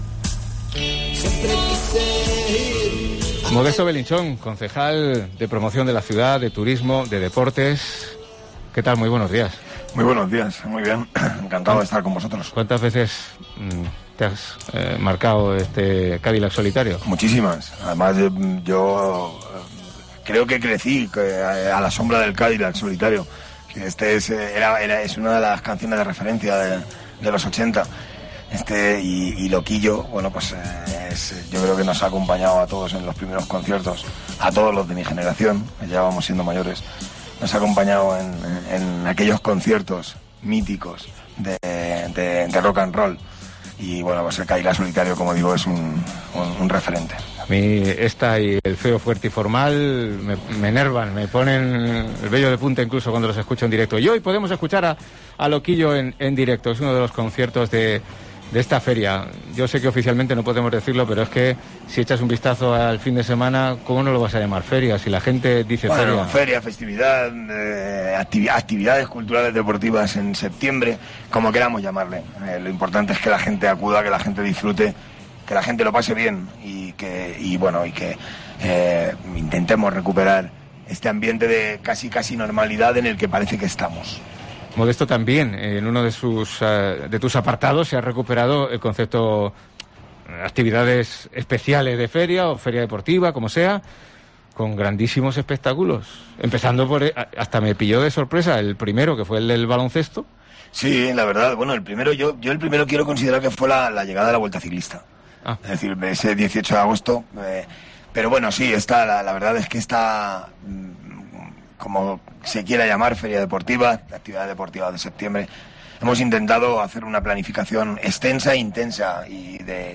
ENTREVISTA COPE
Las actividades deportivas de la Feria y el próximo curso del IMD ya en marcha, temas para esta entrevista emitida en COPE Albacete durante el programa especial desde Don Gil